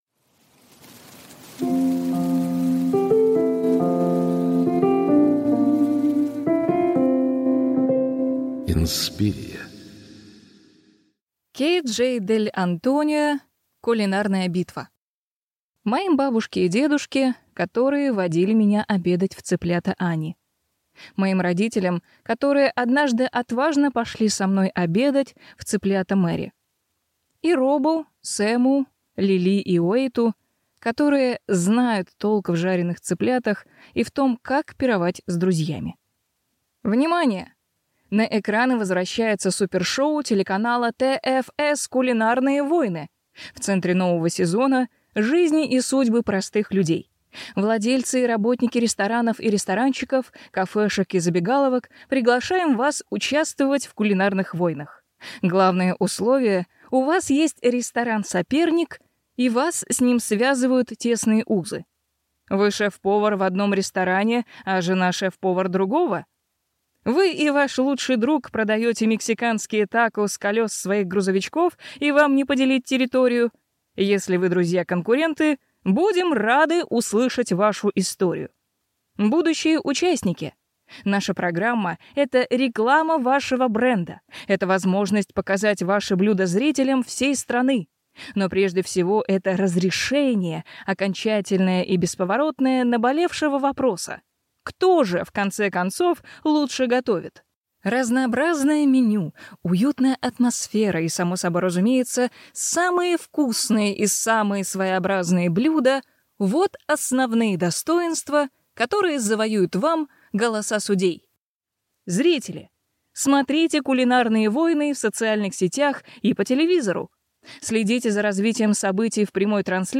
Аудиокнига Кулинарная битва | Библиотека аудиокниг